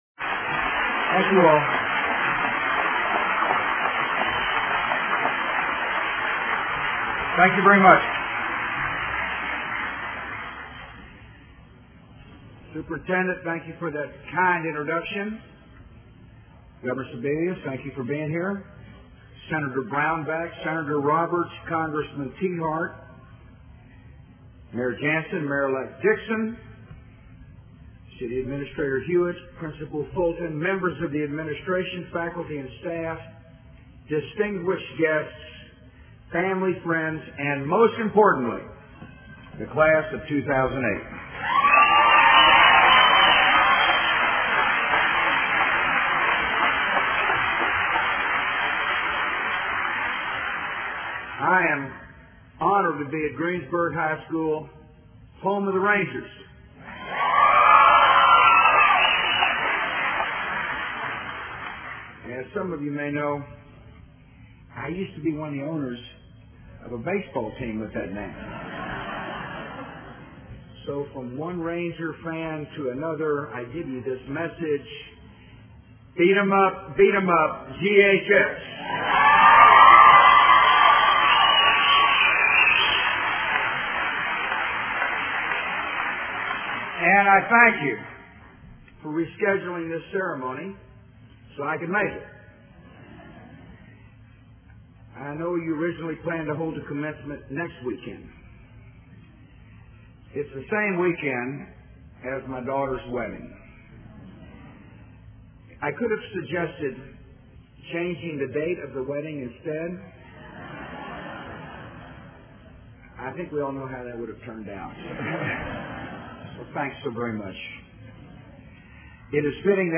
布什参加中学生毕业典礼讲话(2008-05-04) 听力文件下载—在线英语听力室